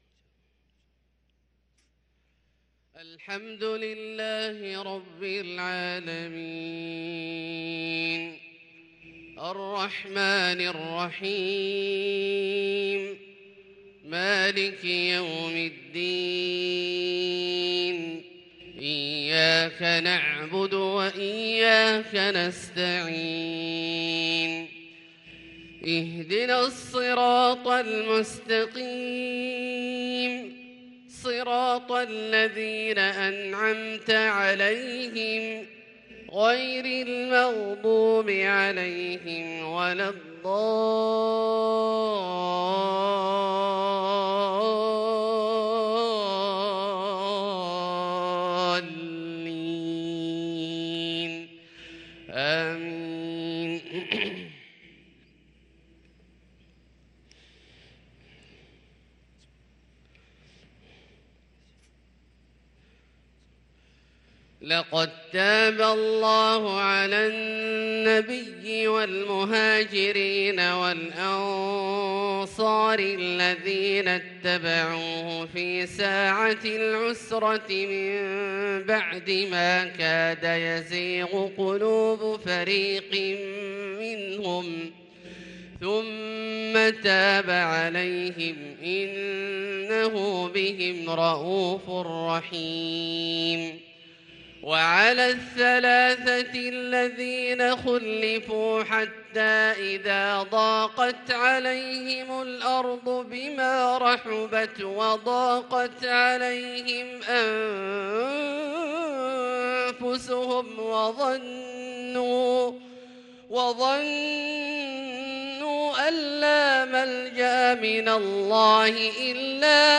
صلاة الفجر للقارئ عبدالله الجهني 3 صفر 1444 هـ